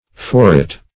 Forewit \Fore"wit`\, n.